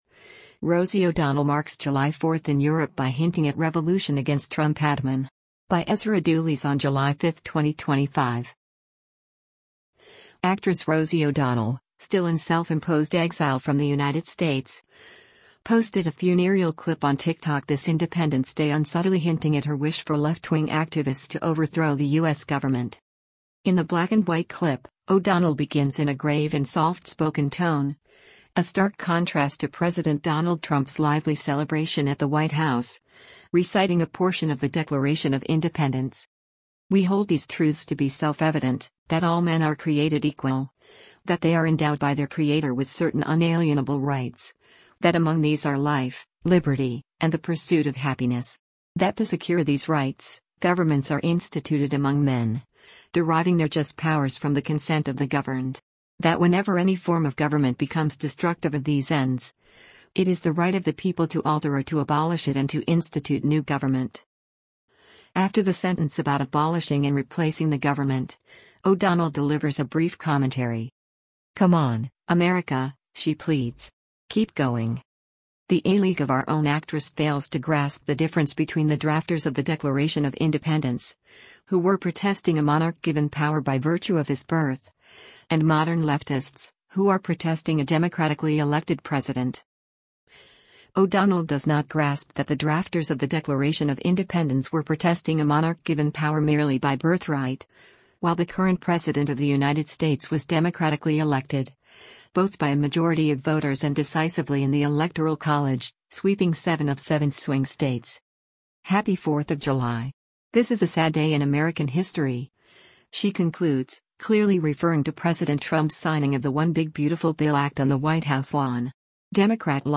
In the black-and-white clip, O’Donnell begins in a grave and soft-spoken tone — a stark contrast to President Donald Trump’s lively celebration at the White House — reciting a portion of the Declaration of Independence: